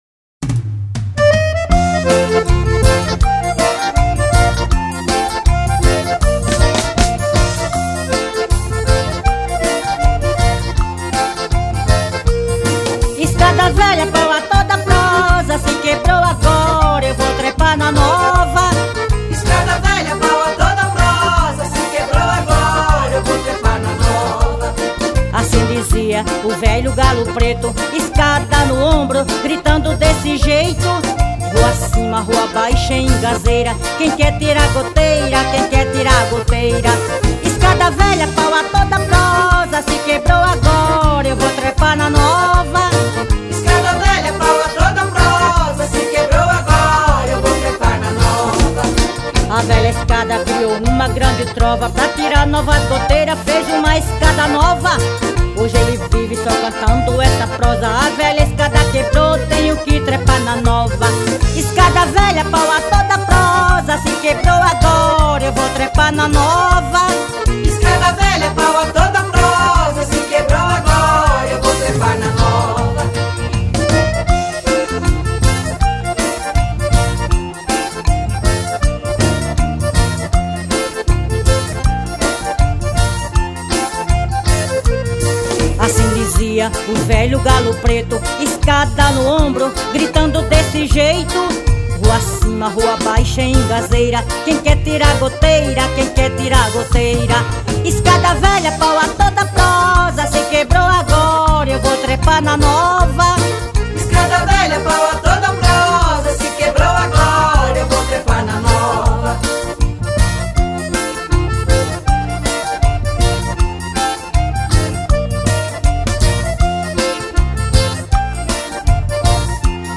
estilo de forró